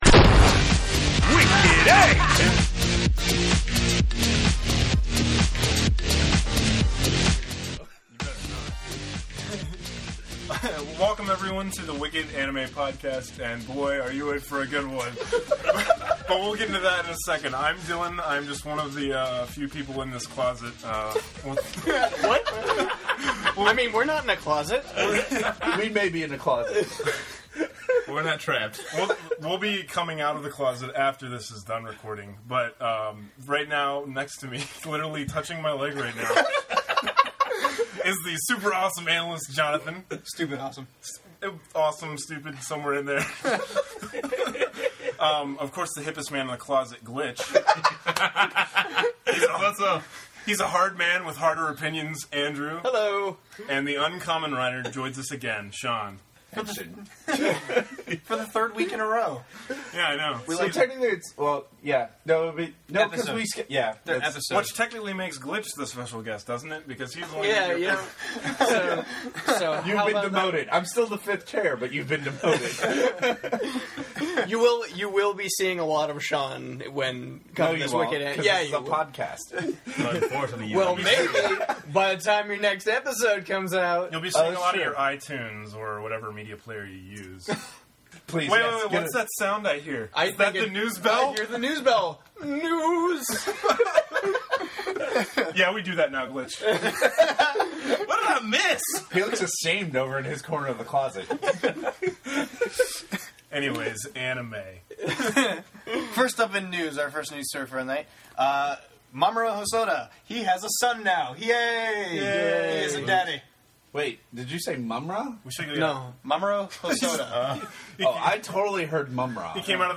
A friend of ours once told us that some of the best podcasts out there have recorded in a closet at least once in their career… and that’s just what we ended up doing this week.